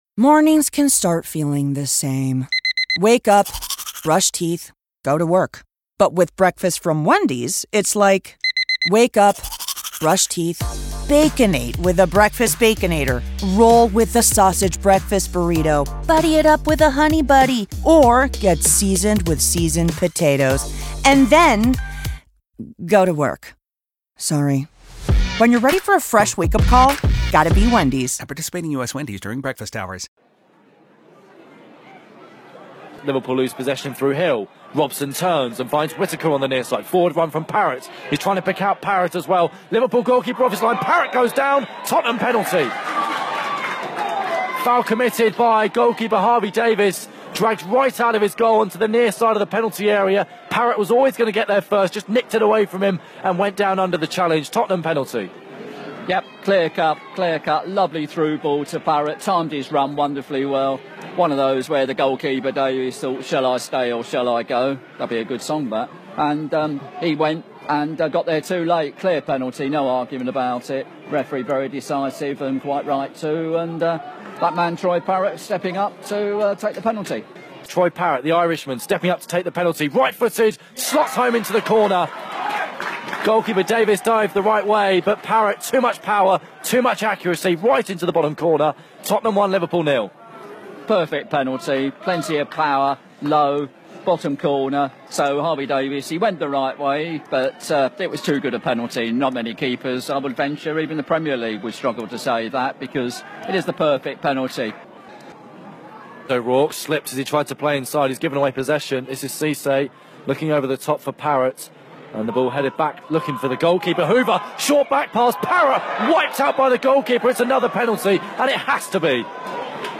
Highlights